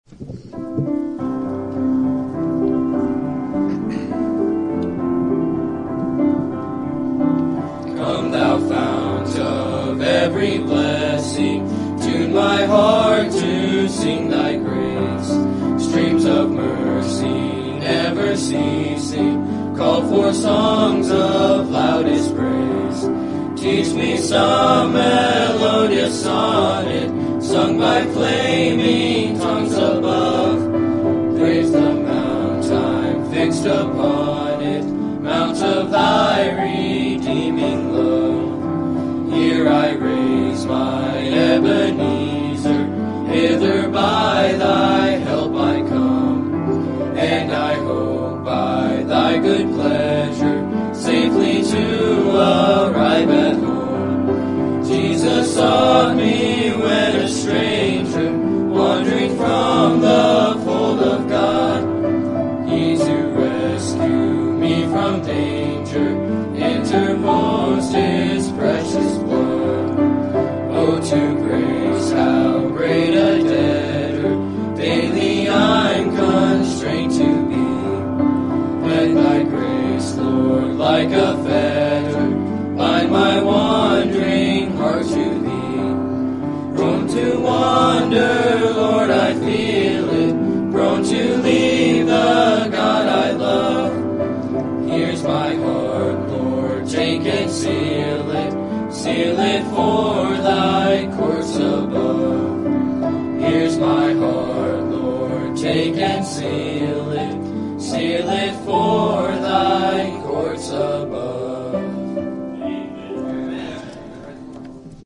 Teen Boys